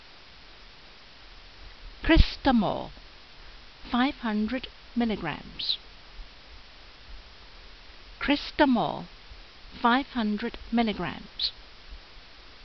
Pronunciation[edit]